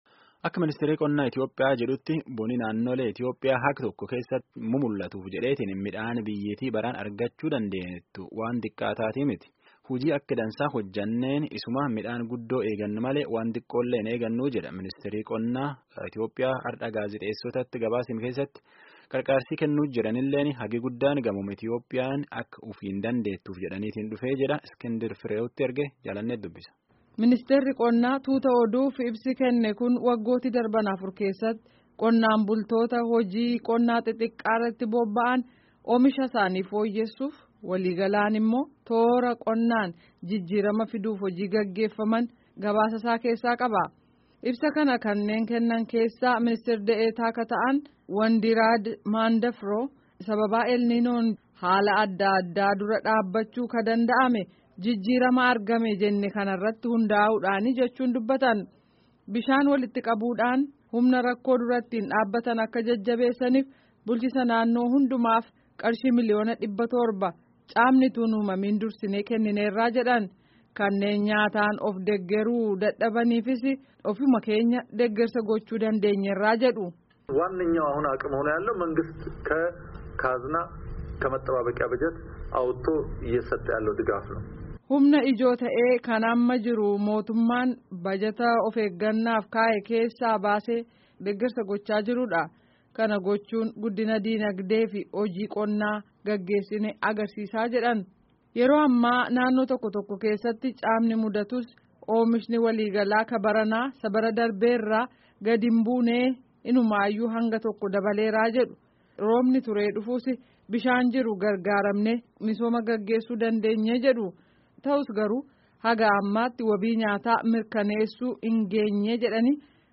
Gabaasa Itiyoopiyaan oomisha midhaaniin of danda'uu ishee ilaalchisee dhiyaate dhaga'aa